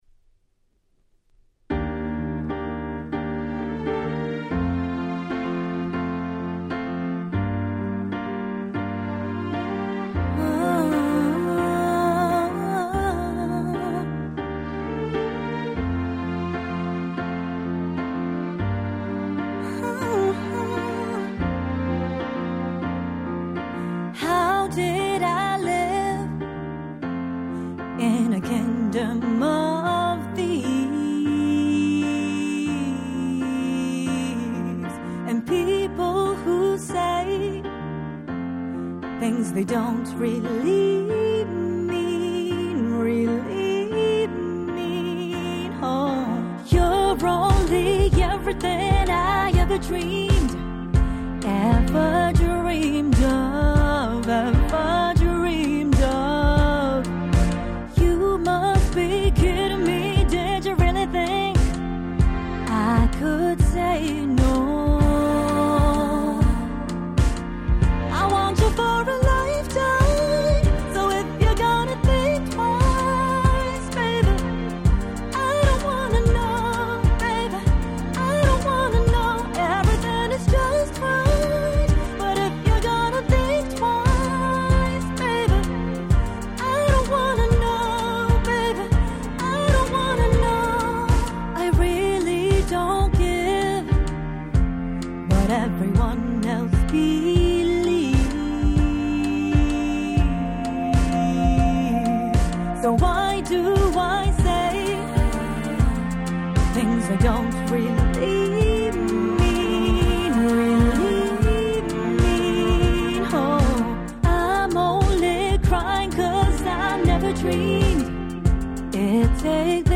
19' Smash Hit Japanese R&B !!